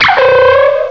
sawk.aif